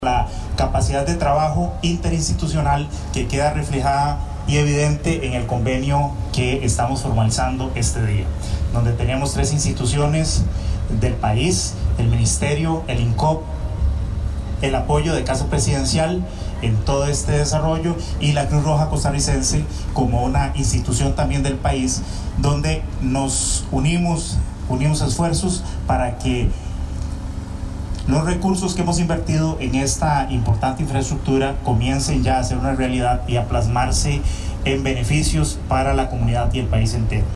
El ministro del MOPT, Germán Valverde, se mostró satisfecho y habló del convenio entre estas tres instituciones.